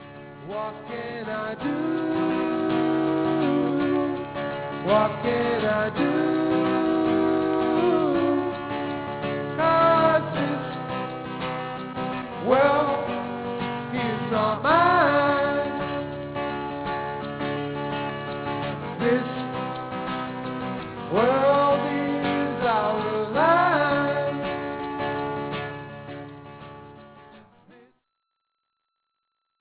A folk song about me against the world!